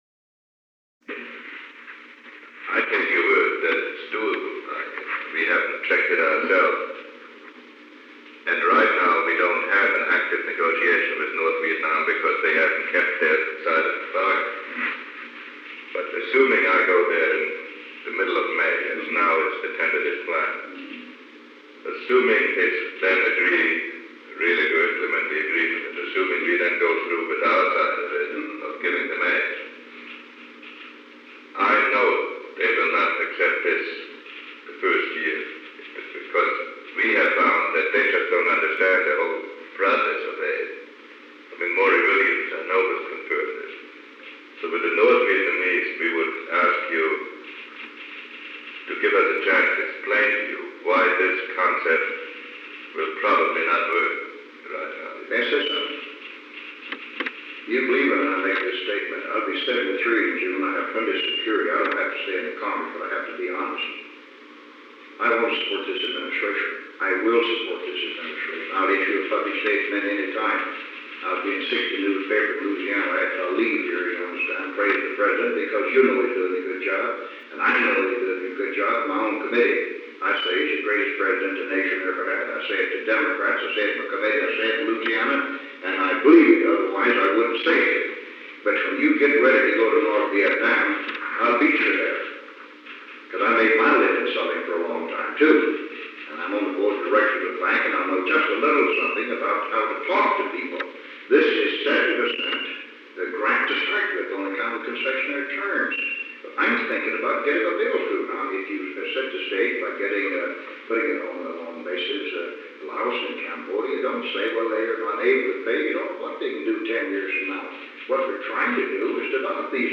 Conversation No. 910-1 Date: May 2, 1973 Time: Unknown between 5:10 pm and 5:49 pm Location: Oval Office [Continued from Conversation No. 909-30] An unknown portion of this conversation was not recorded while the audiotape reels were changed. The President met with Otto E. Passman, John Hannah, William E. Timmons, and Henry A. Kissinger.
Secret White House Tapes